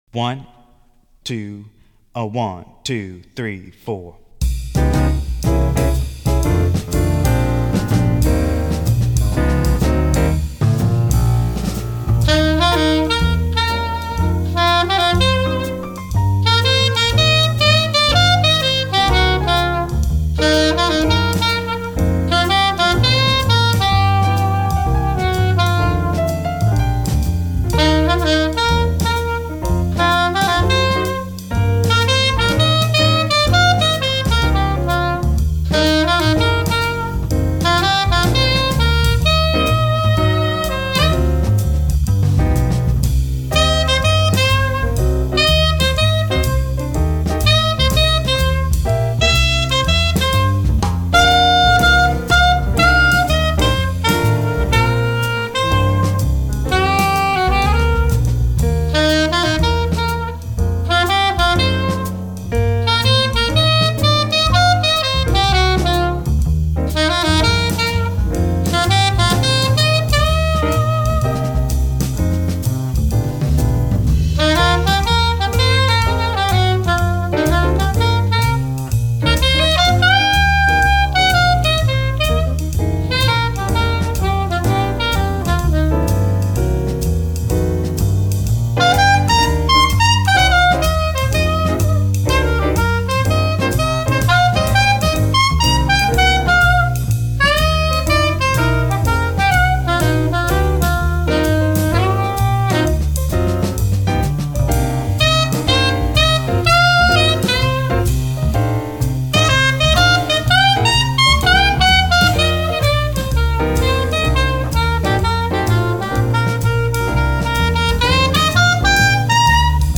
Ich hatte heute die kleine Tröte (Sopranino) und den Micro BR mit hier oben.
Die Aufnahme ist mit dem internen Micro vom Boss gemacht, was für ein Sopranino bestimmt nicht so super ist. Ist auch komplett im Boss gemischt worden.
Nur die Tonlage ist mir (eine Oktave) zu hoch.
edith: "quitschen" tust du darauf jedenfalls klasse :-ß